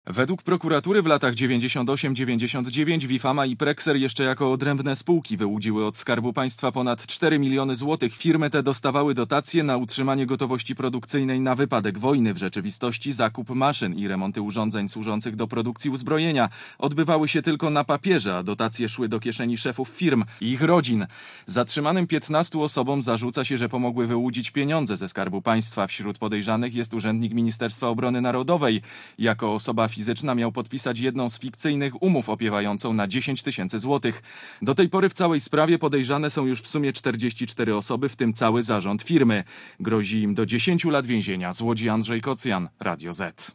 Relacja reportera Radia Zet (340Kb)